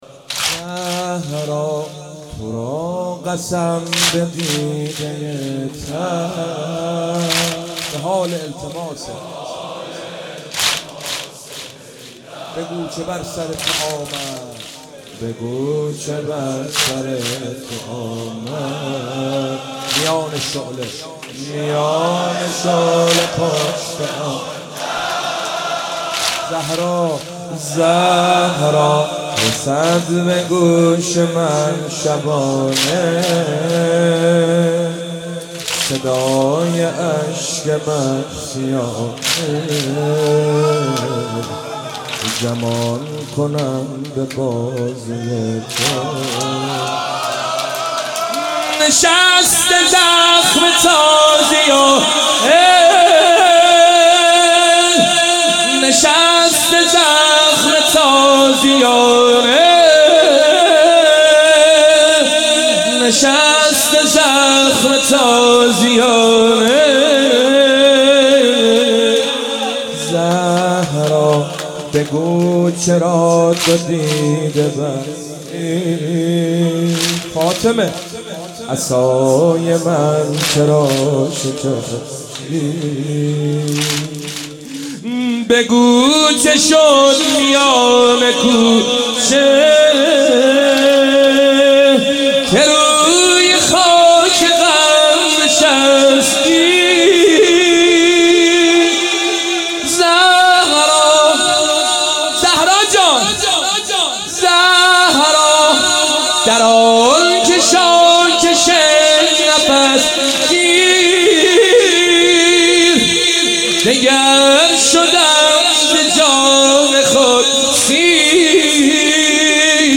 خیمه حضرت فاطمه زهرا سلام الله علیها
ایام فاطمیه 95 - 7 اسفند 95 - واحد - زهرا تو را قسم به دیده تر
گلچین فاطمیه